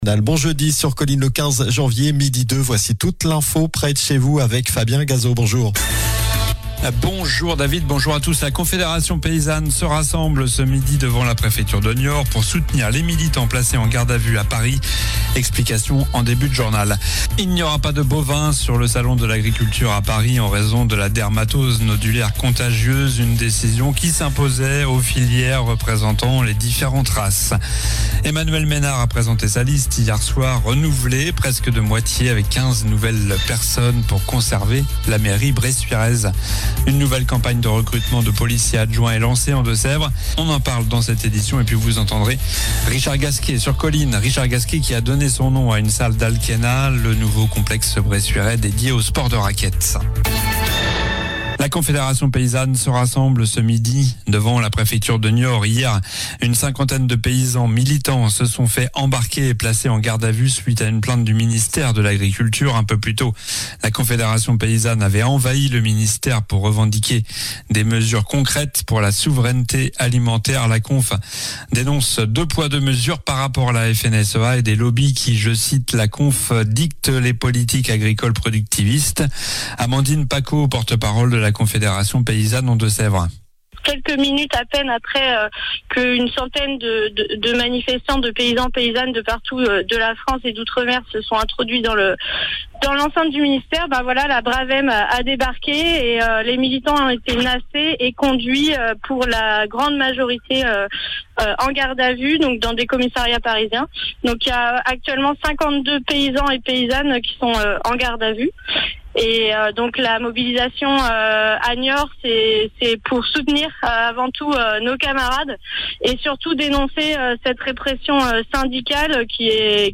Journal du jeudi 15 janvier (midi)